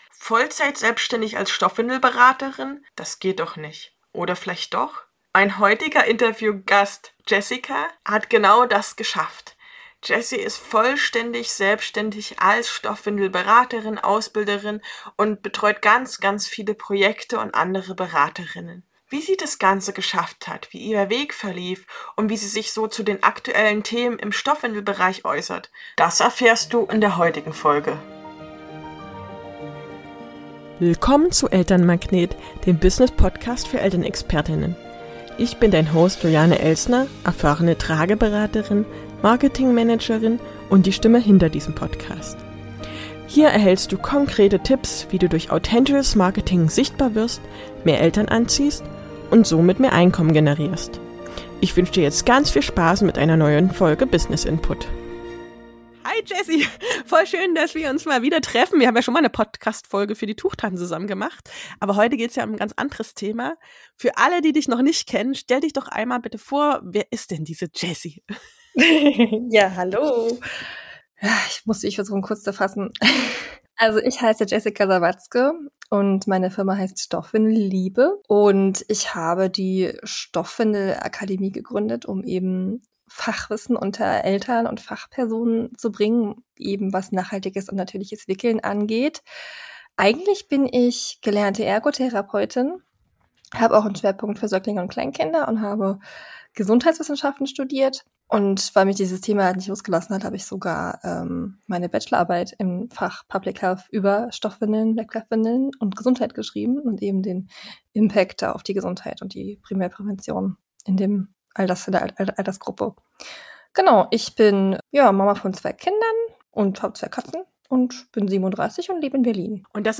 In dem Interview teilt sie ihre Tipps dazu mit uns.